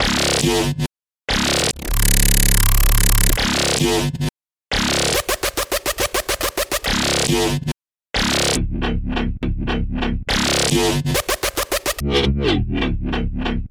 Dubstep Stuff Wobble 2
描述：噪声重采样的随机下降（LF）。
Tag: 140 bpm Dubstep Loops Bass Wobble Loops 2.31 MB wav Key : C